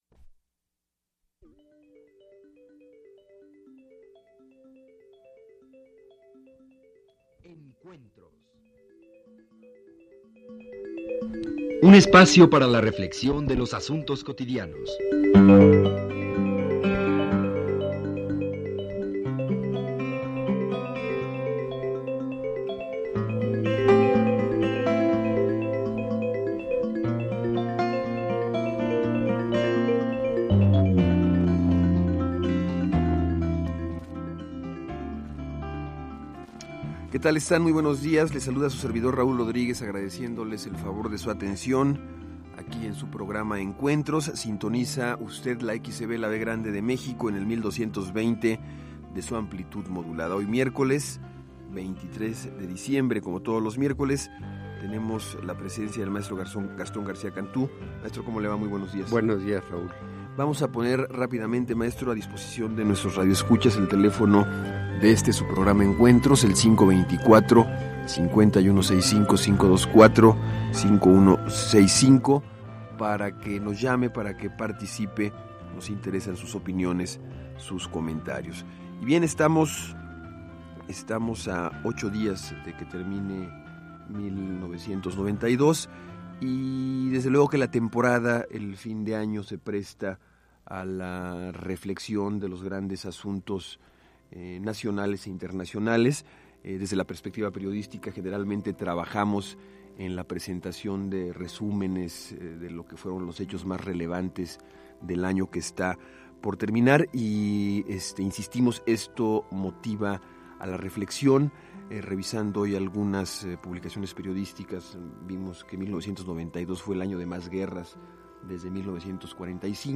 Escucha a Gastón García Cantú en el programa “Encuentros”, transmitido en 1992, en el que presenta el tema de la pobreza en México.